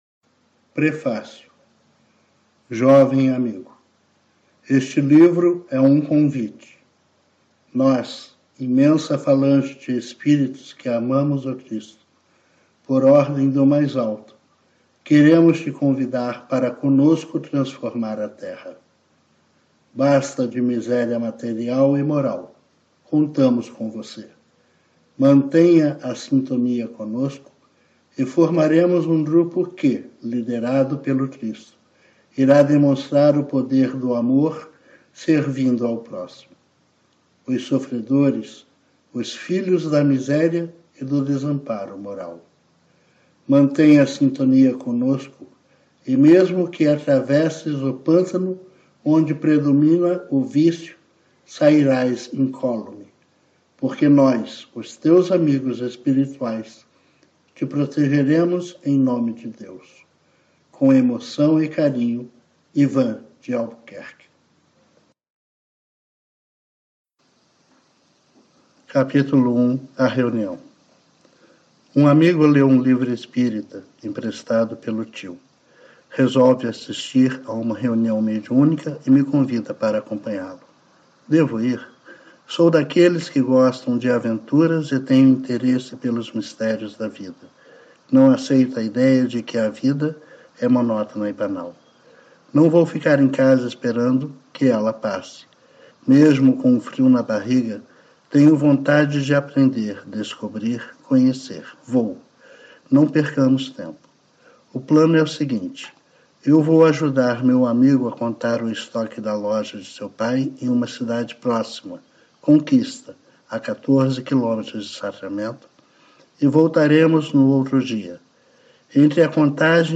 Grupo Marcos - Áudio livro- Meu Amigo Eurípedes Barsanulfo